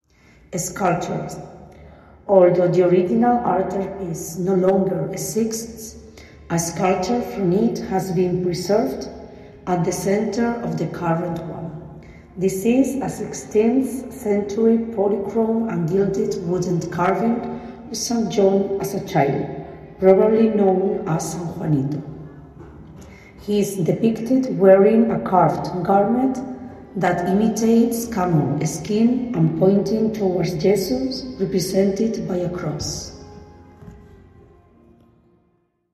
Bloque Principal de la Entidad Ayuntamiento de Segura de León .escultura4 Escultura / Sculpture usted está en Capilla del Sagrario / Tabernacle Chapel » Escultura /Sculpture Para mejor uso y disfrute colóquese los auriculares y prueba esta experiencia de sonido envolvente con tecnología 8D.